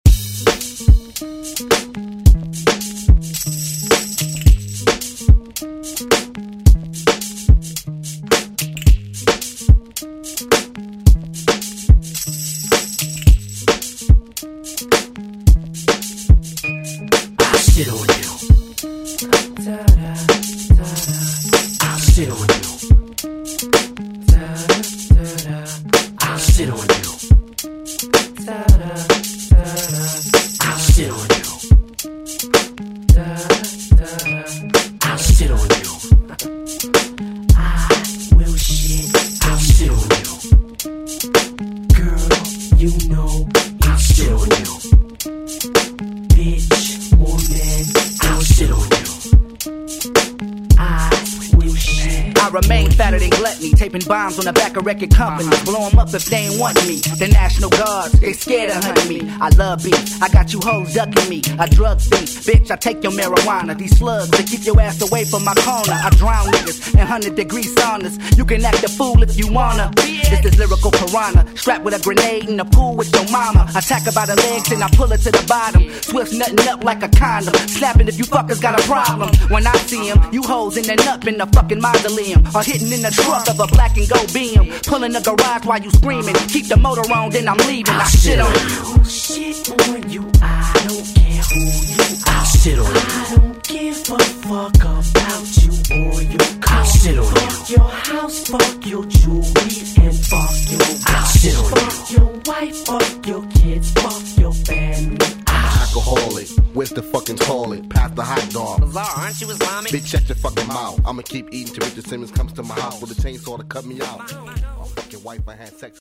Electronic House Ragga Dancehall Music
108 bpm